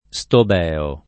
Stobeo [ S tob $ o ]